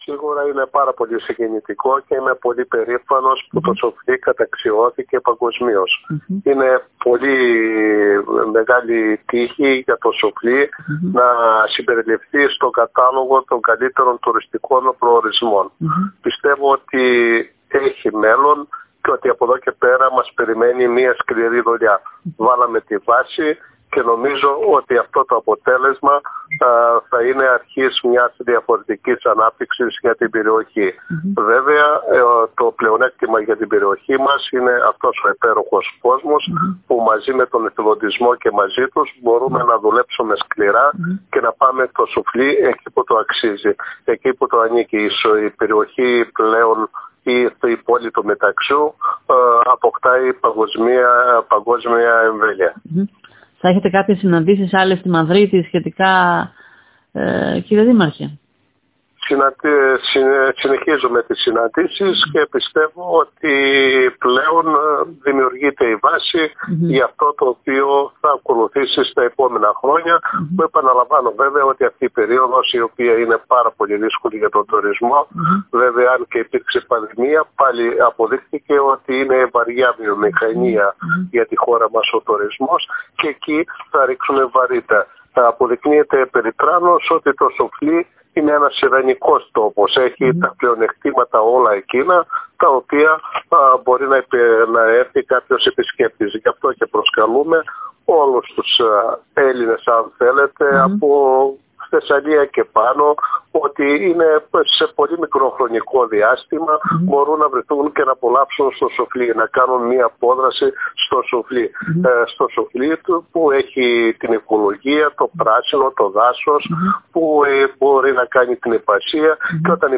Θρίαμβος για το Σουφλί στα “Best Tourism Villages” «Το Σουφλί εισέρχεται στον Παγκόσμιο Τουριστικό Χάρτη» δήλωσε με περηφάνεια στην ΕΡΤ Ορεστιάδας ο δήμαρχος Παναγιώτης Καλακίκος επισημαίνοντας ότι το αποτέλεσμα αυτό είναι η αρχή μιας ανάπτυξης για την περιοχή και προϋποθέτει σκληρή δουλειά απ’ όλους φορείς και απλούς πολίτες.»